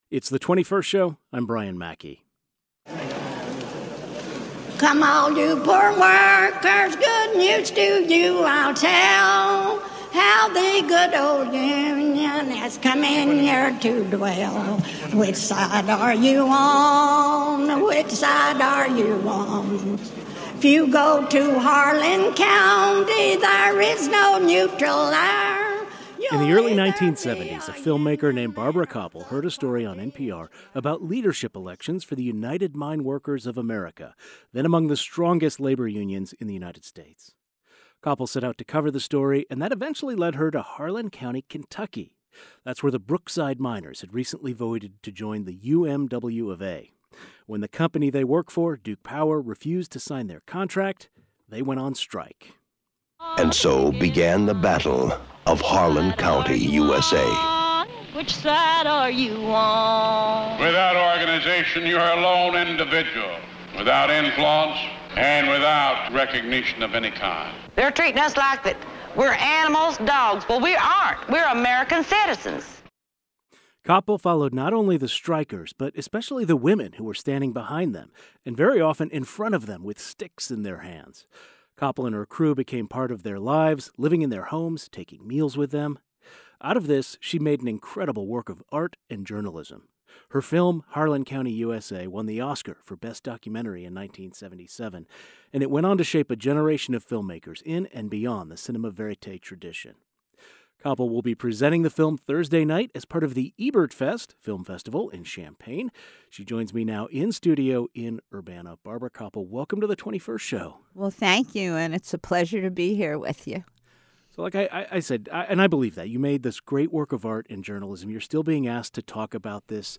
GUEST Barbara Kopple Filmmaker, "Harlan County, U.S.A." Academy Award Winner